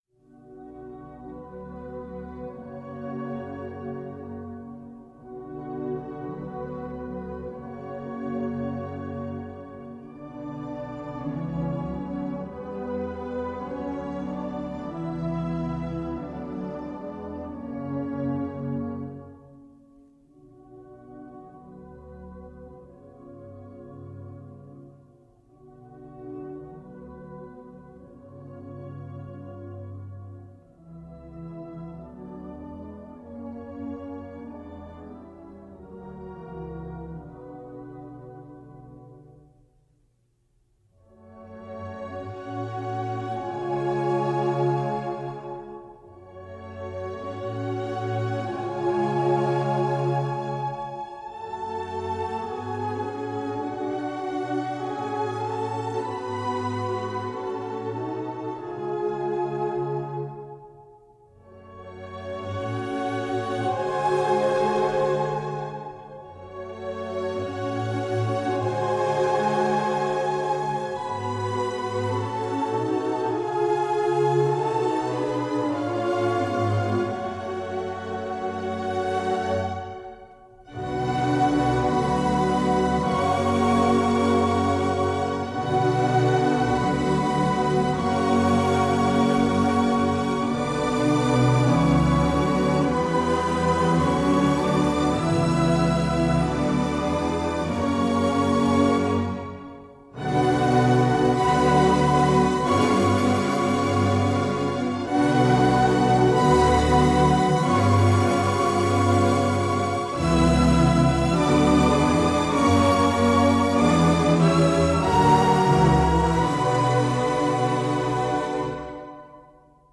그리그-페르 귄트 중에서 오제의 죽음/칼멘 드라곤 (지휘) 캐피털 시티 교향악단
Edvard Grieg - The Death Of Åse (Peer Gynt)Carmen Dragon(Cond) Capital City Symphony